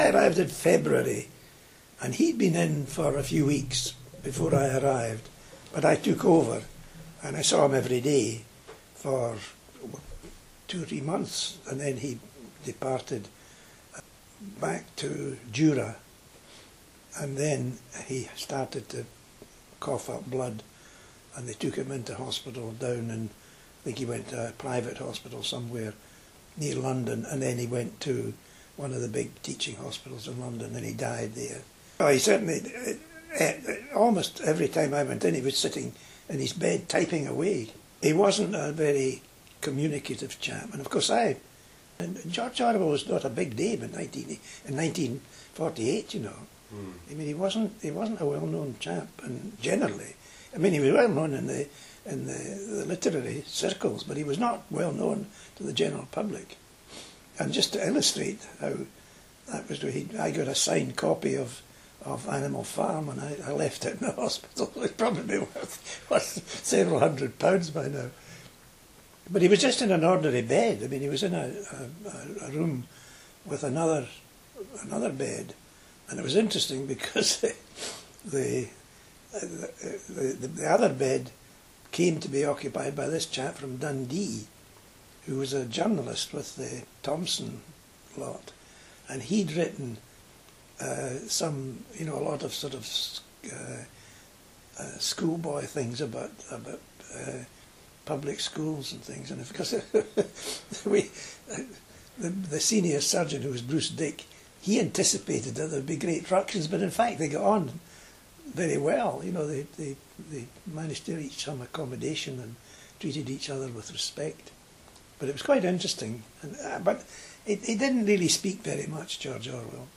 Here are two interview clips previously available on the NHS 60th anniversary website – the first on Orwell